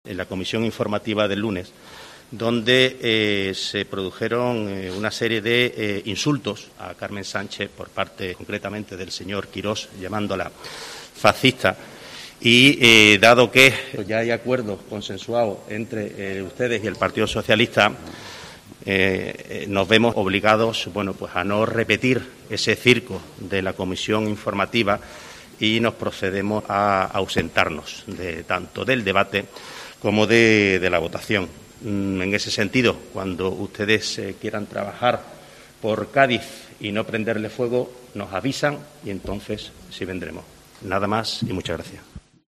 Juancho Ortiz, anuncia que los concejales del Partido Popular en el Ayuntamiento de Cádiz abandonan el Pleno